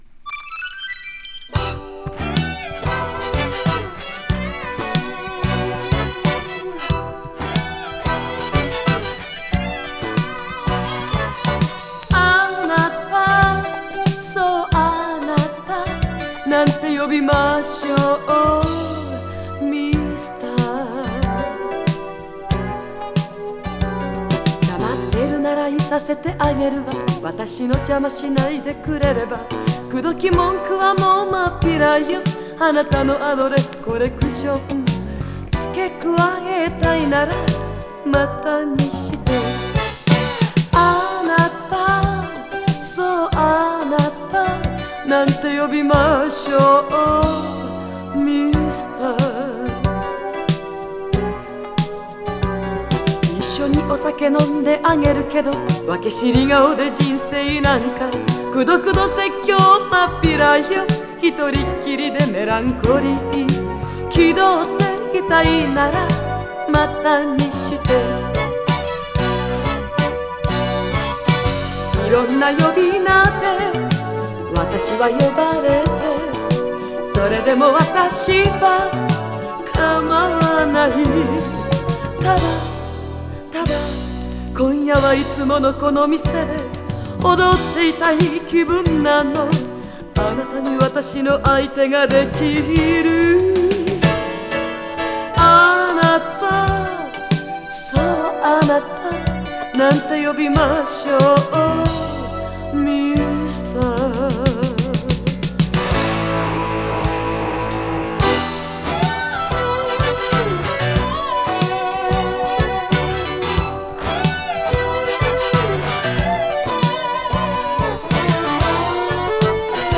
宝塚星組トップスター時代の歌唱力が存分に発揮された珠玉の一枚で、ミュージカル曲のカバーも収録。